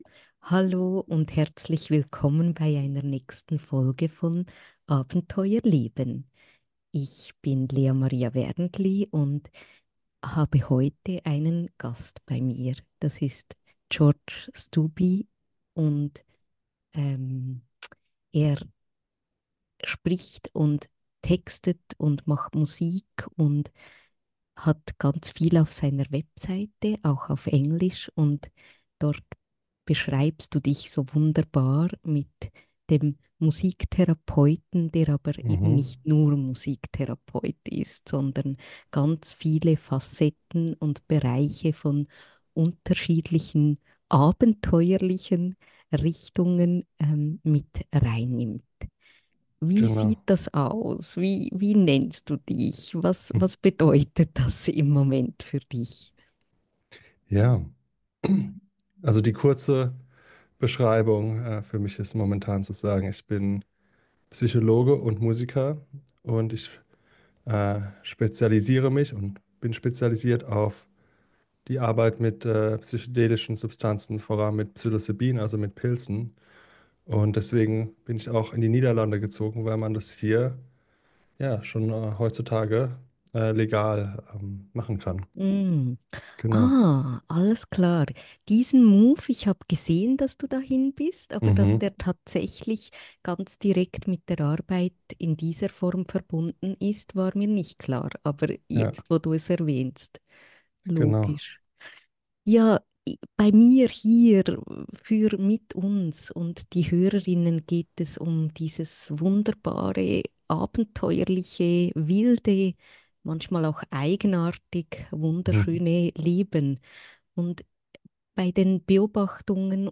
Wir tauchen ein in seine Sichtweise auf Lebendigkeit, Heilung, Integration und die Bedeutung, der eigenen Essenz zu folgen. Ein inspirierendes Gespräch über die Verbindung von Kreativität und Transformation, die Kraft der Musik und die Möglichkeiten, authentisch zu leben – ohne auf einschneidende Erlebnisse warten zu müssen.